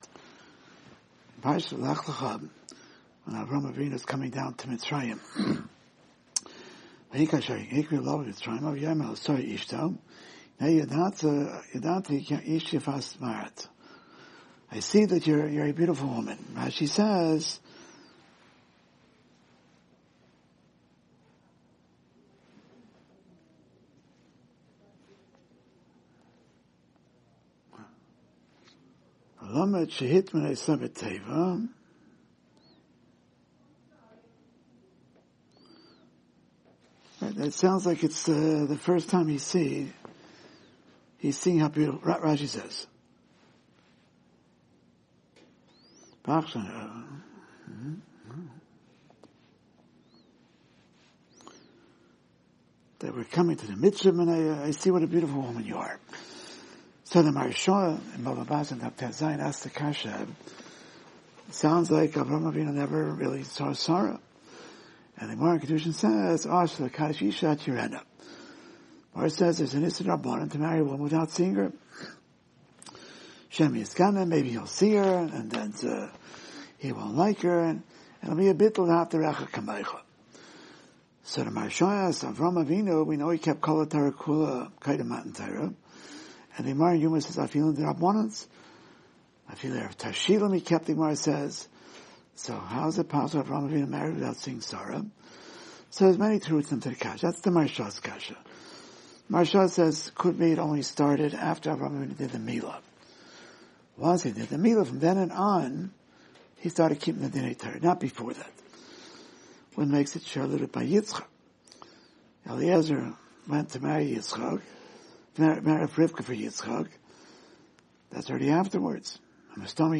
Weekly Alumni Shiur - Ner Israel Rabbinical College